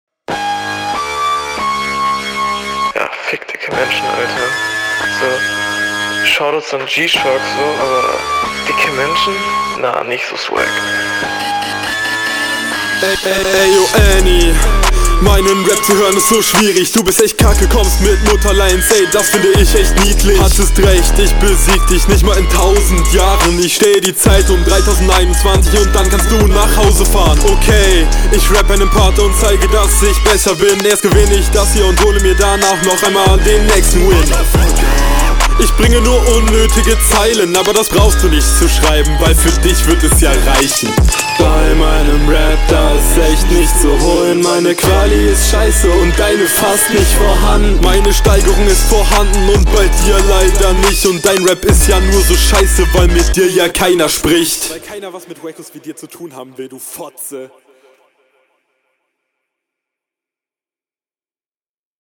Rückrunde 2
Flow: Sehr nicer Flow!
Flow: Sehr gut geflowt, gefällt mir fast besser als auf eigenem Beat.